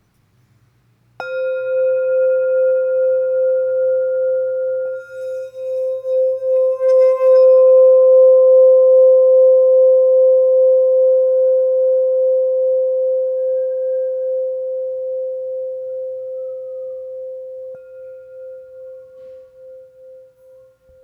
C Note 6″ Singing Bowl